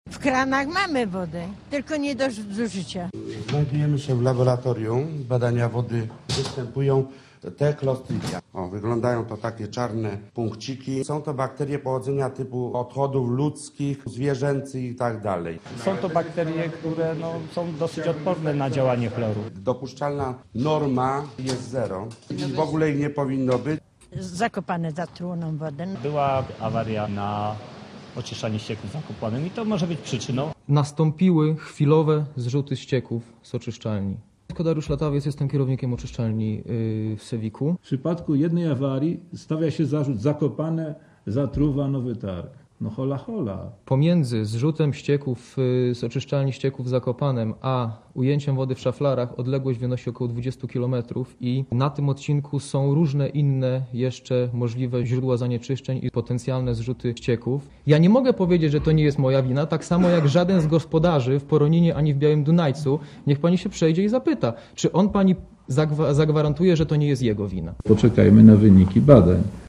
**Posłuchaj relacji korespondenta Radia Zet (0.5 MB)